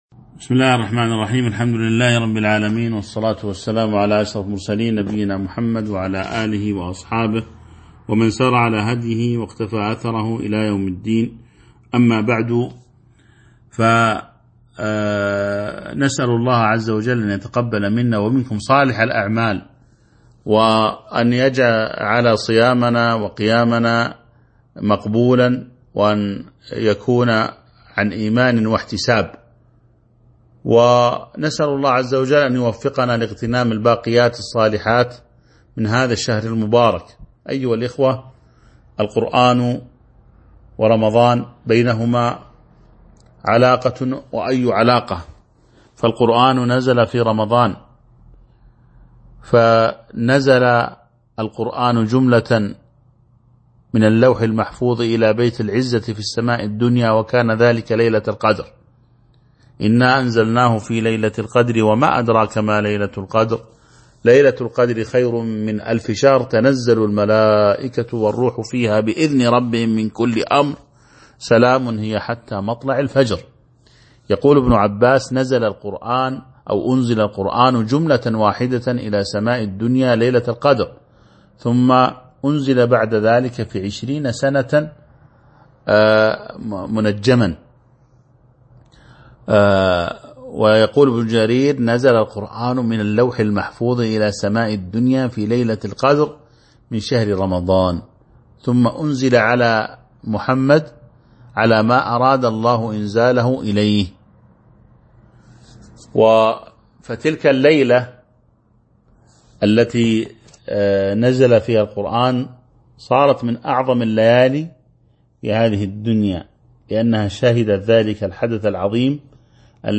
تاريخ النشر ١٣ رمضان ١٤٤٢ هـ المكان: المسجد النبوي الشيخ